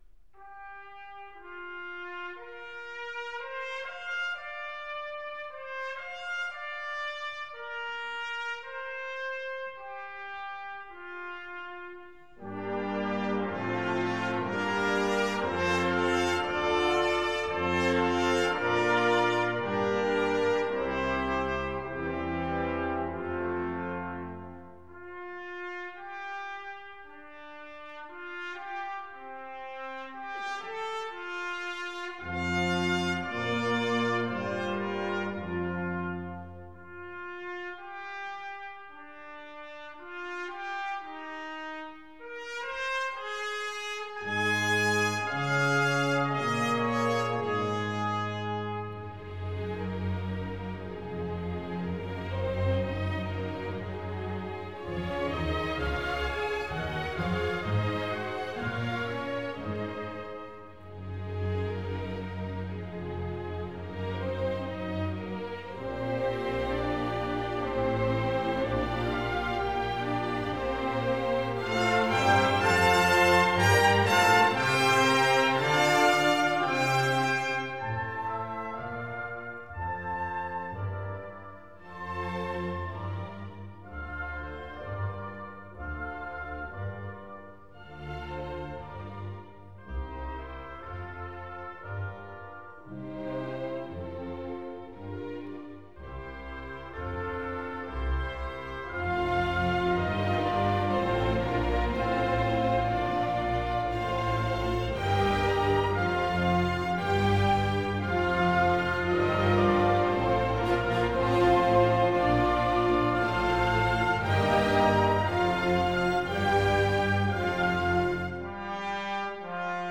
03 - М. Мусоргский - Картинки с выставки (оркестровка М. Равеля)  1. - Promenade.mp3